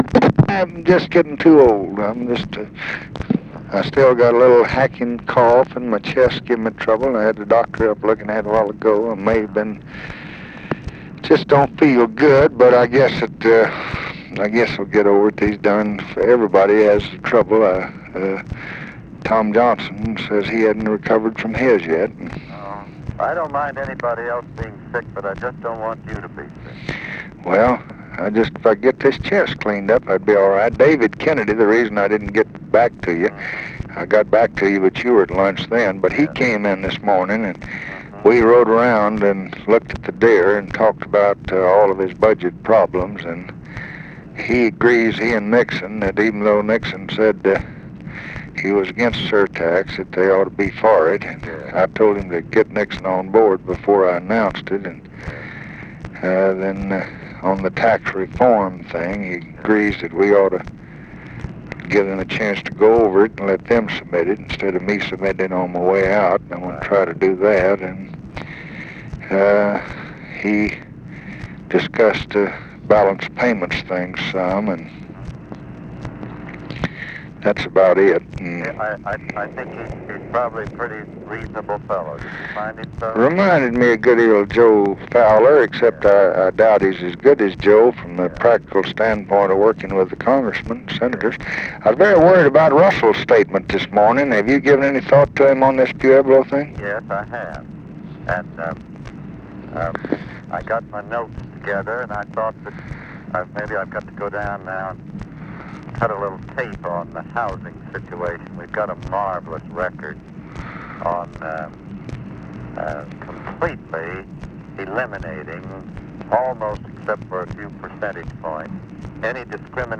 Conversation with CLARK CLIFFORD, December 31, 1968
Secret White House Tapes